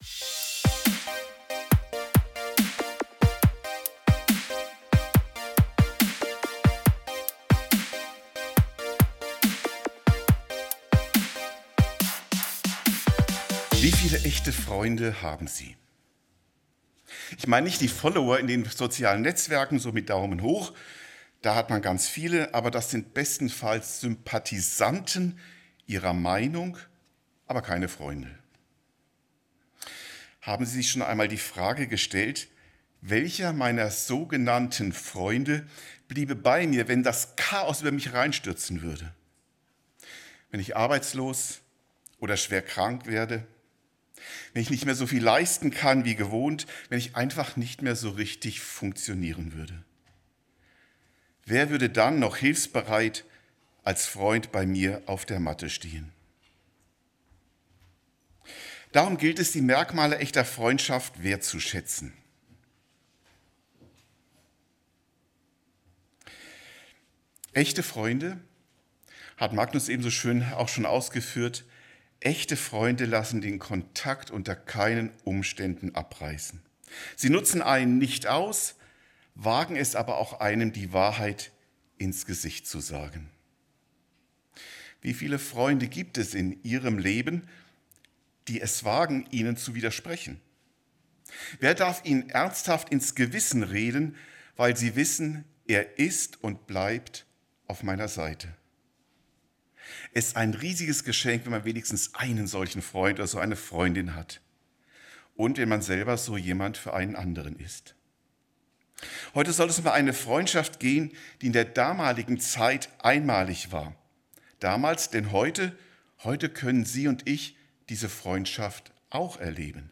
Eine himmlische Freundschaft - Mose und Gott ~ Predigten u. Andachten (Live und Studioaufnahmen ERF) Podcast